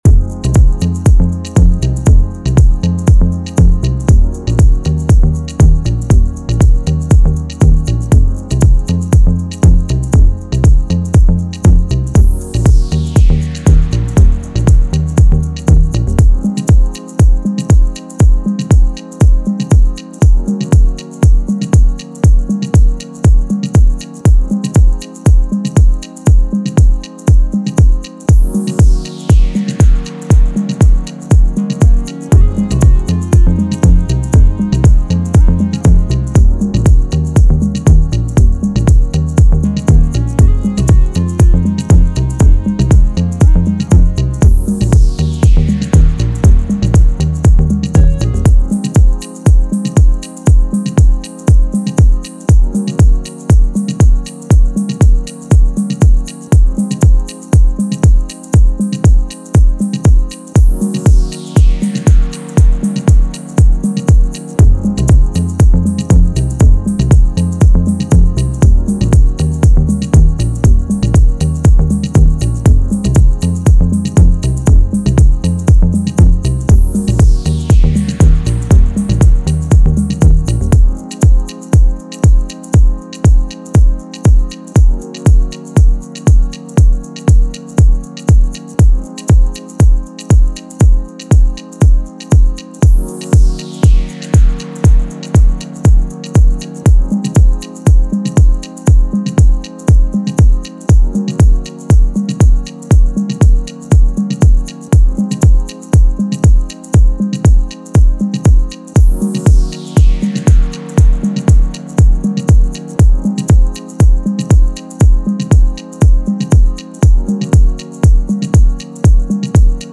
House, Dance
C#maj